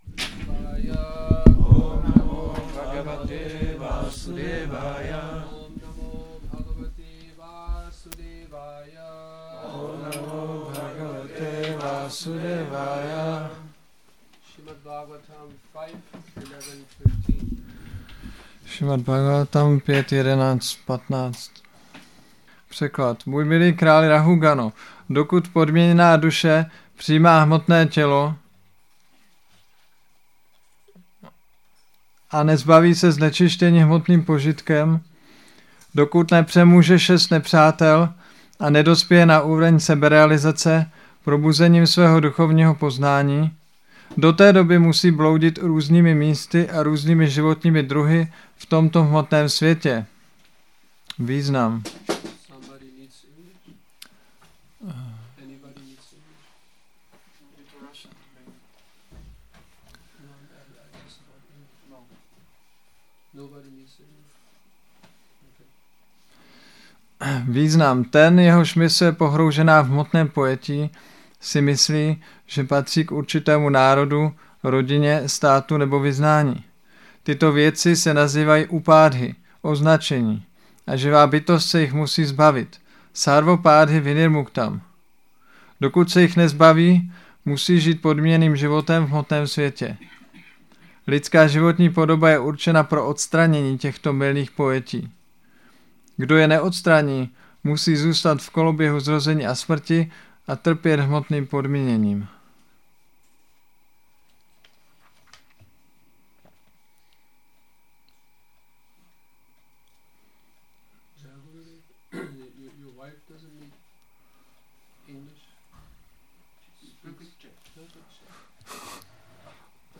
Přednáška SB-5.11.15 – Šrí Šrí Nitái Navadvípačandra mandir